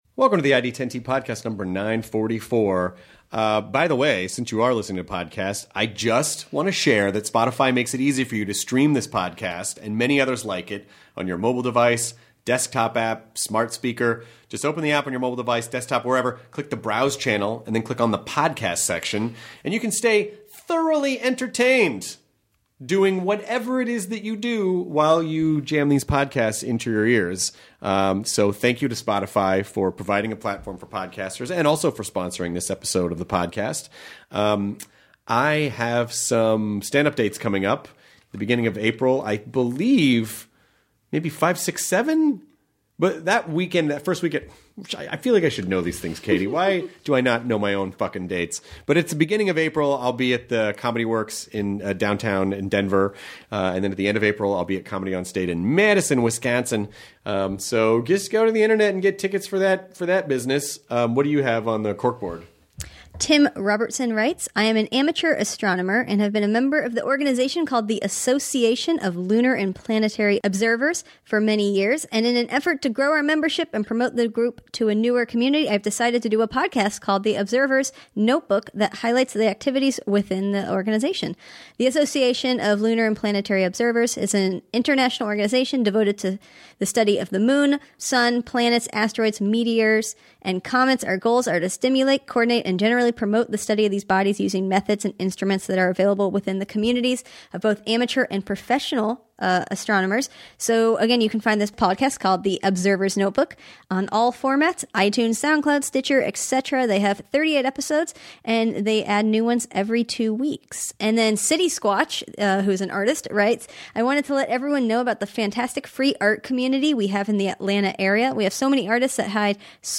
Paul Reiser (Mad About You, My Two Dads, stand up) talks with Chris about how he got his start in comedy, the big comedy boom of the 80s and getting started as a sitcom actor. He talks about his time on Mad About You, coming up with new jokes and his show There’s….Johnny on Hulu!